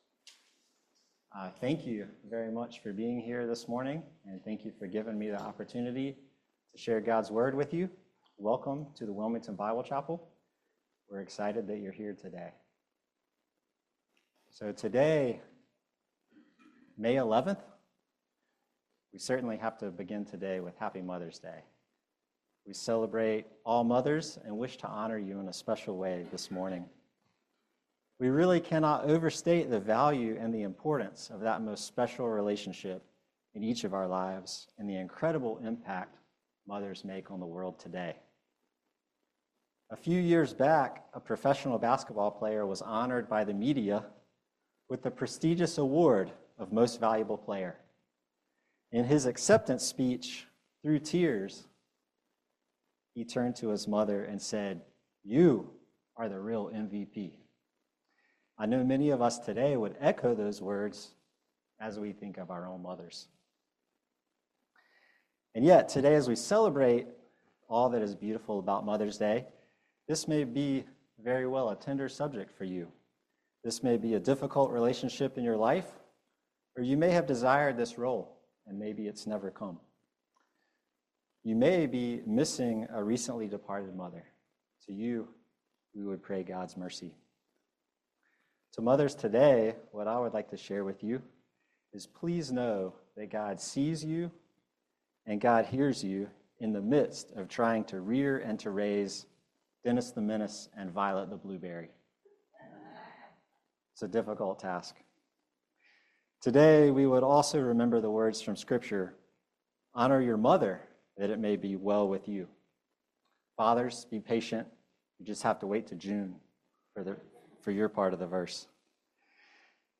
John 4 Service Type: Family Bible Hour The God who sees and hears provides the water springing up to eternal life.